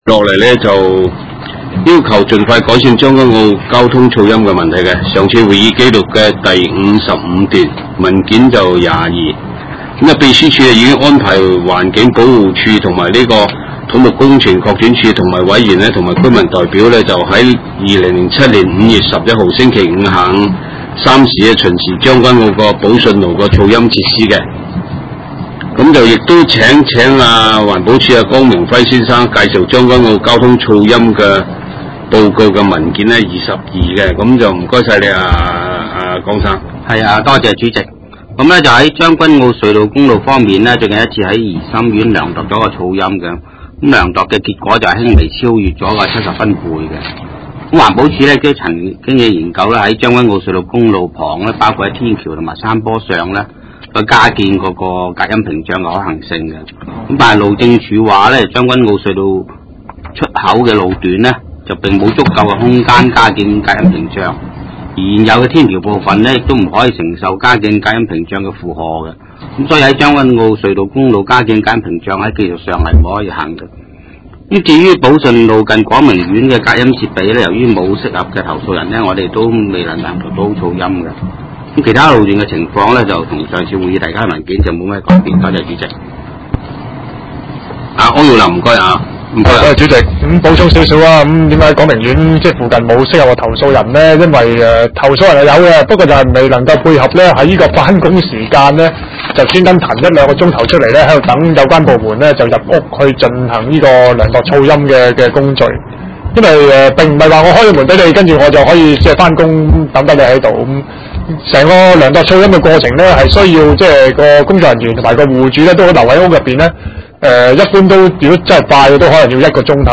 地點：西貢區議會會議室